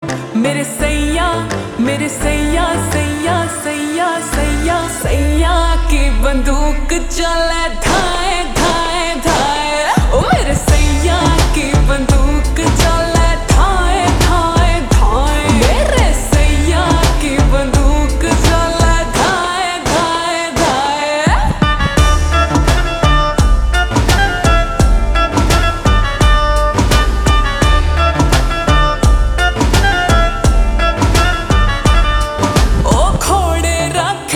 ( Slowed + Reverb)